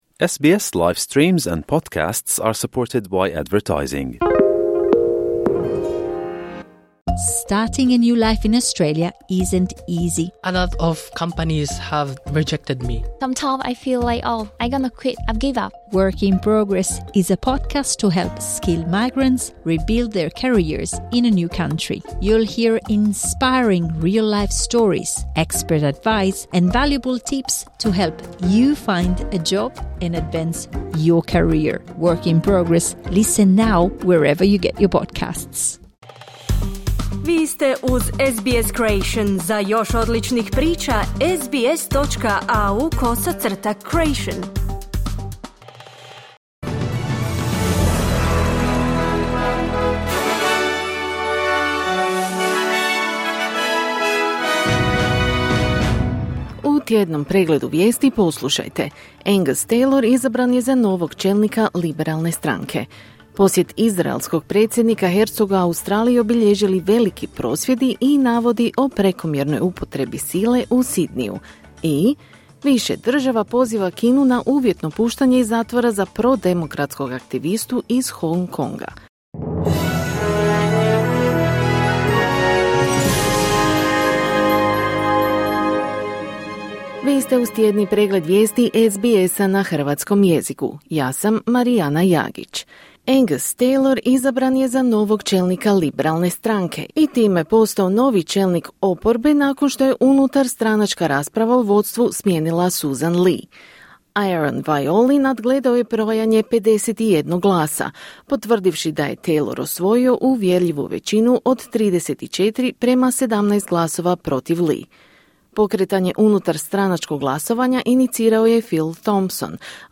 Tjedni pregled vijesti, 13.2.2026.
Vijesti radija SBS na hrvatskom jeziku.